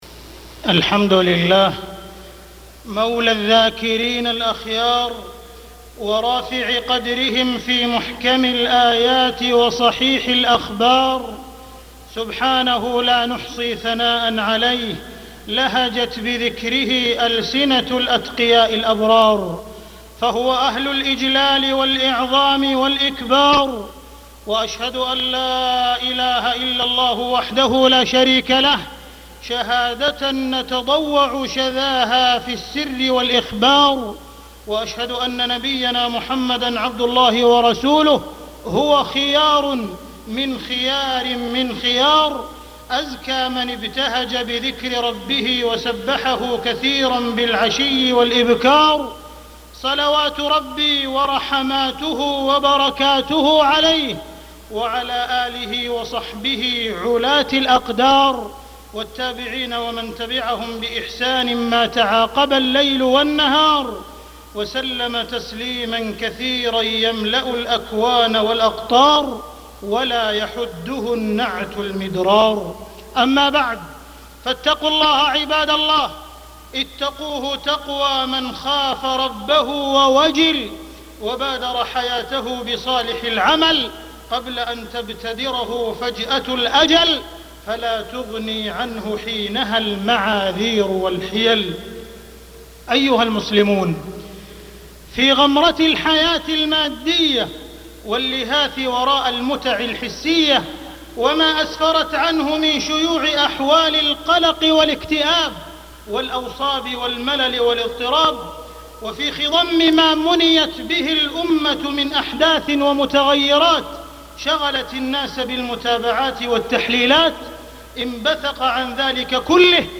تاريخ النشر ١٢ ربيع الثاني ١٤٢٦ هـ المكان: المسجد الحرام الشيخ: معالي الشيخ أ.د. عبدالرحمن بن عبدالعزيز السديس معالي الشيخ أ.د. عبدالرحمن بن عبدالعزيز السديس ولذكر الله أكبر